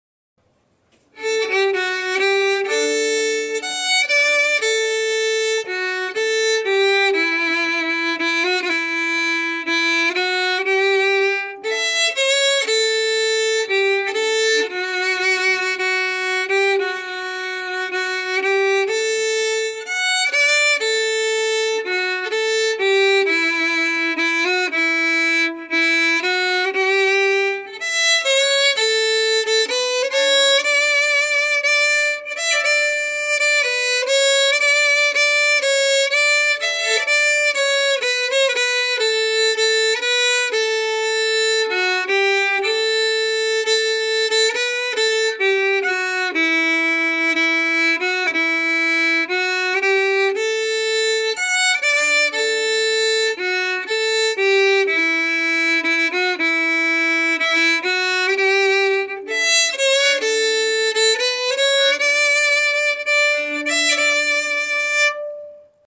Key: D
Form: Two-step and song
Played slowly for learning
M:2/2